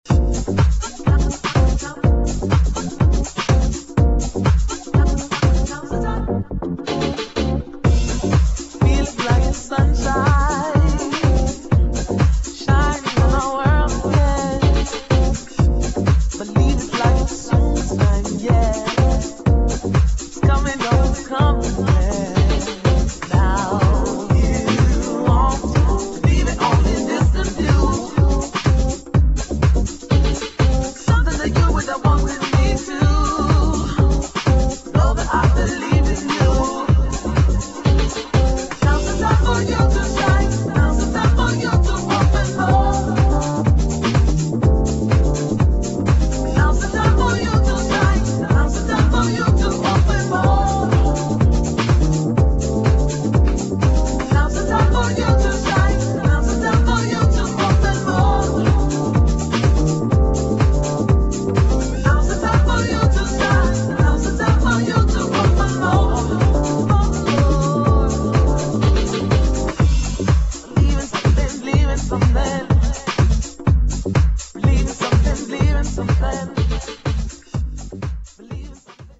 [ HOUSE / JAZZ HOUSE ]